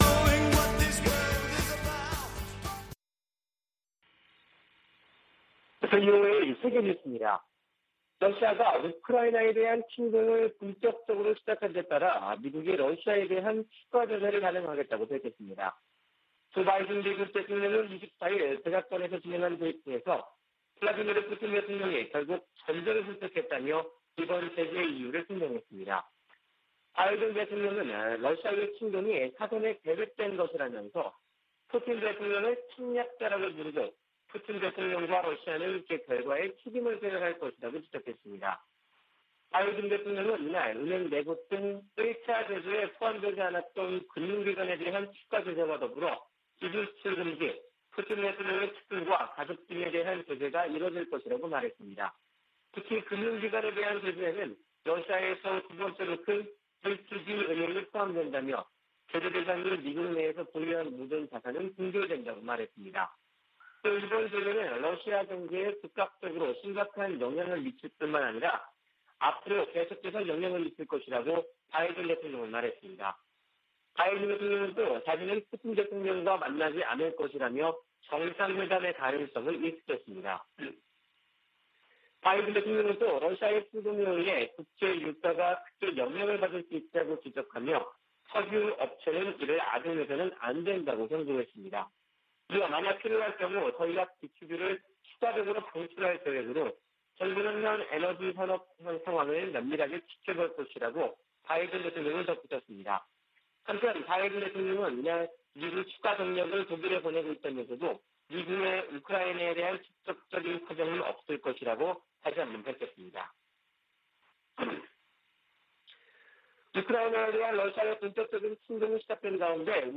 VOA 한국어 아침 뉴스 프로그램 '워싱턴 뉴스 광장' 2021년 2월 25일 방송입니다. 러시아의 우크라이나 침공으로 미-러 갈등이 격화되고 있는 가운데 북한의 외교 셈법이 복잡해졌다는 분석이 나오고 있습니다. 미 국방부는 우크라이나에 대한 한국의 지지 성명에 주목했다고 밝혔습니다. 미국의 전통적 대북 접근법으로는 북한 문제를 해결하는 데 한계가 있으며, 대통령의 리더십이 중요하다는 보고서가 나왔습니다.